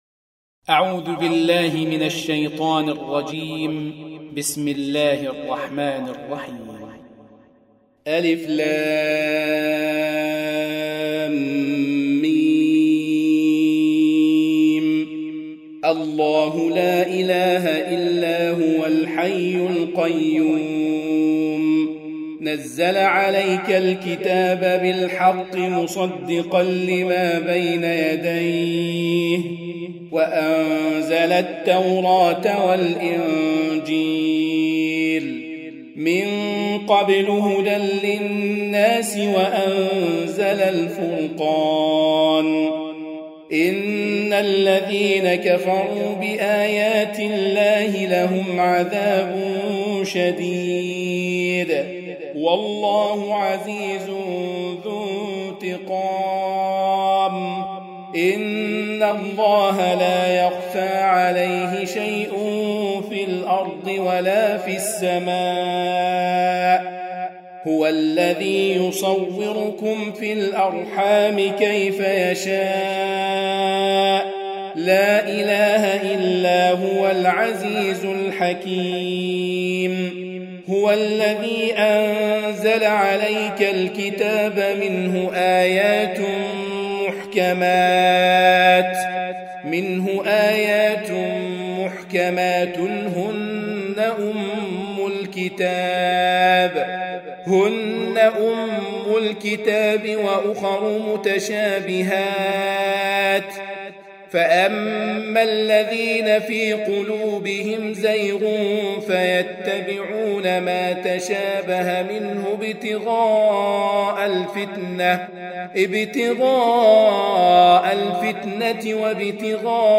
Surah Repeating تكرار السورة Download Surah حمّل السورة Reciting Murattalah Audio for 3. Surah �l-'Imr�n سورة آل عمران N.B *Surah Includes Al-Basmalah Reciters Sequents تتابع التلاوات Reciters Repeats تكرار التلاوات